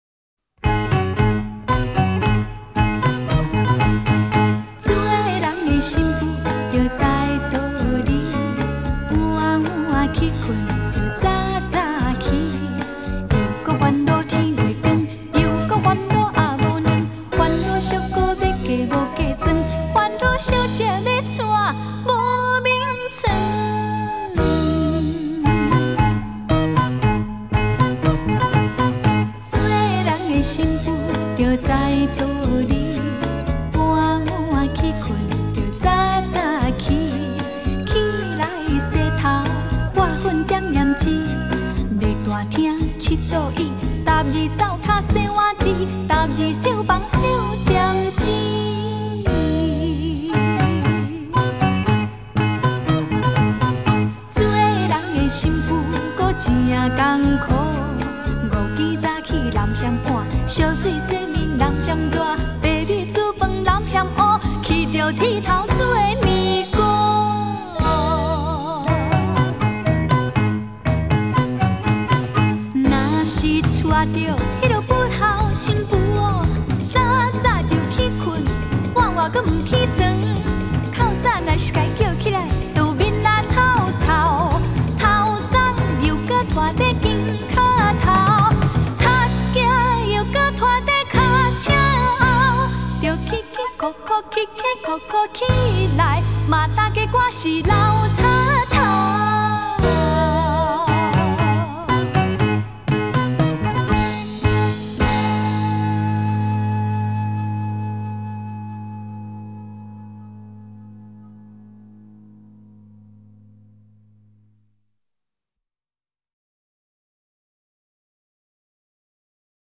音质好的